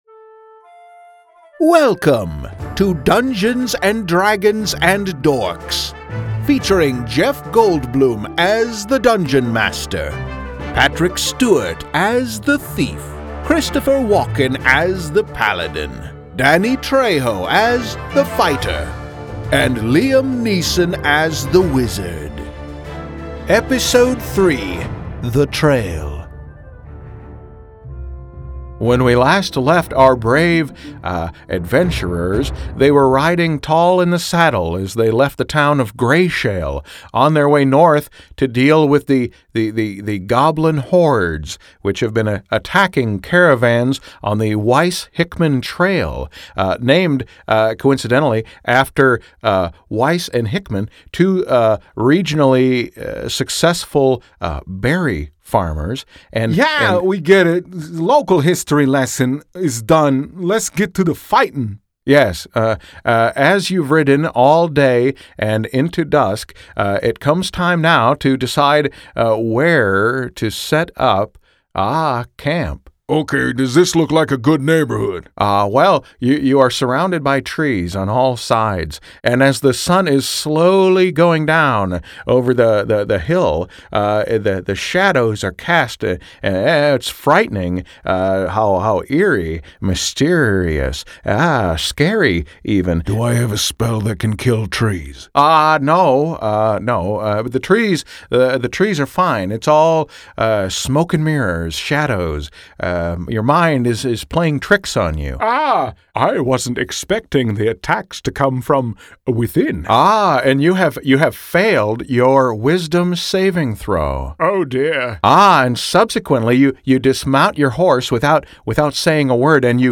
If you’re just finding this audio series (featuring celebrity impressions), I strongly urge you to take about 12 minutes and catch up with the previous episodes:
Tags: celebrity impressions, voice over